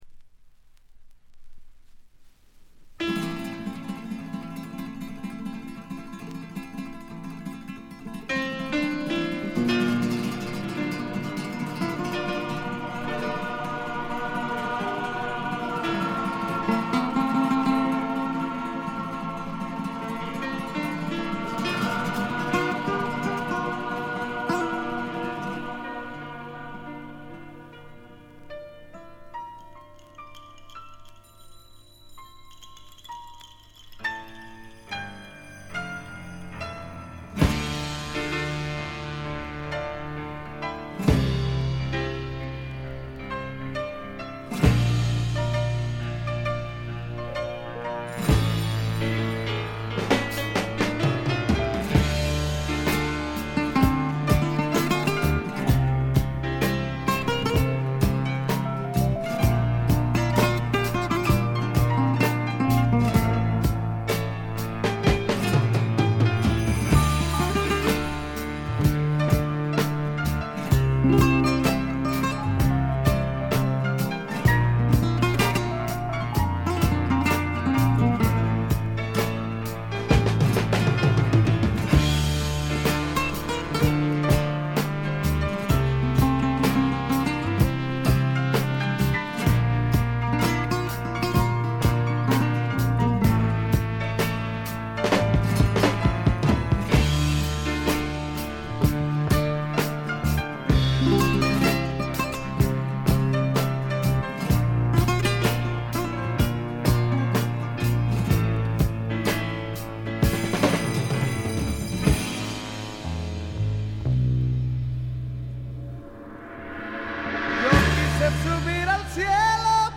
ほとんどノイズ感無し。
スペインの誇るスパニッシュ・プログレ、フラメンコ・プログレの超絶名盤です。
フラメンコ・ギターの美しい調べにくらくらしてください！
試聴曲は現品からの取り込み音源です。